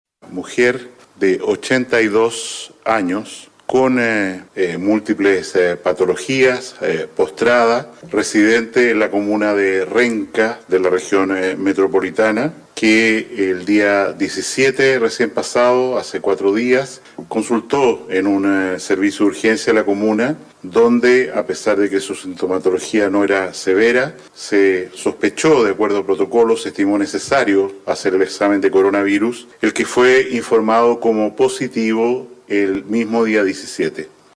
De acuerdo a los antecedentes entregados posteriormente por la primera autoridad de salud, en conferencia de prensa, detalló que se trata de una mujer de 82 años, que se encontraba postrada, y que era residente de la comuna de Renca, en la región metropolitana.